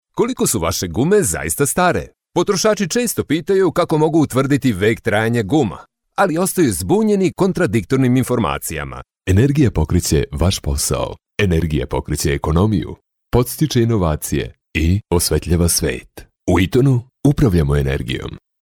Serbian male voice over